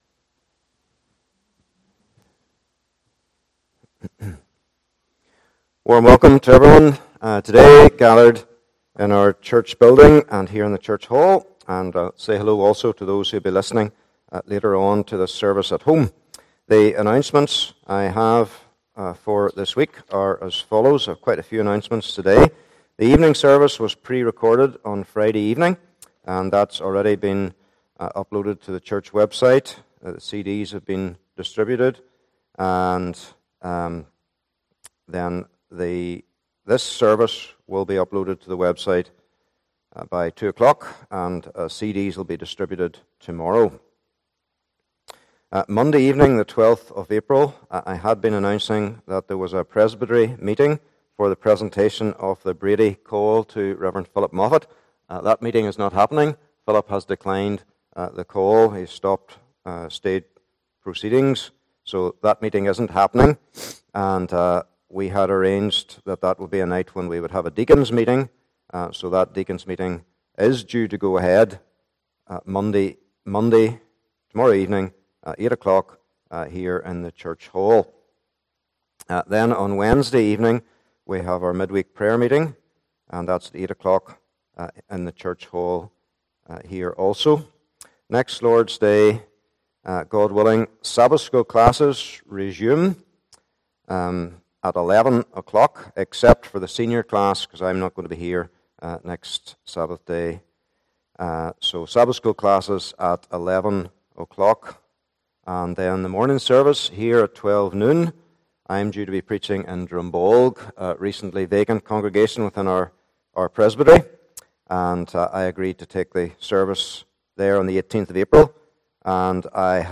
1 Corinthians Passage: 1 Corinthians 4 : 8 - 21 Service Type: Morning Service « Don’t Look at Me